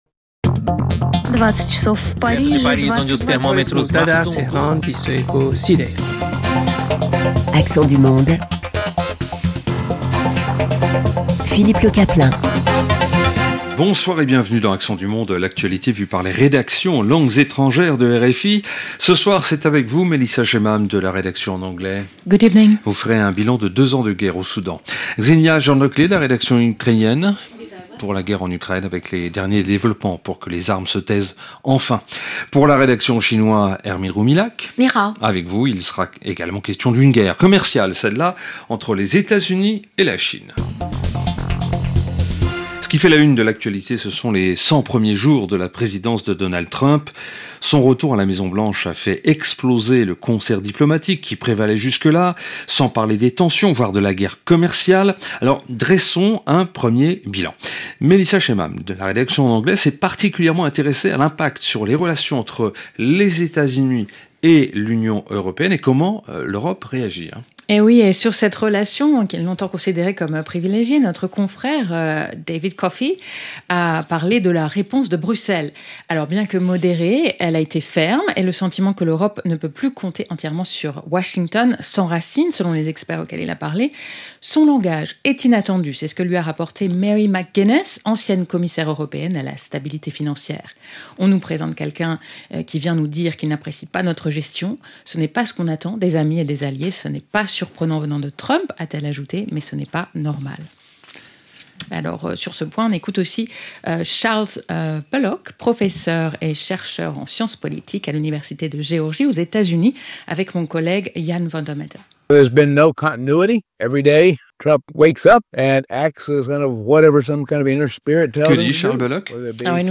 Le vendredi soir, dans Accents du monde, les journalistes des rédactions en langues étrangères croisent leurs regards, en français, sur l’actualité internationale et partagent les événements et les faits de société de leur région.